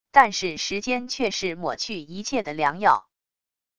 但是时间却是抹去一切的良药wav音频生成系统WAV Audio Player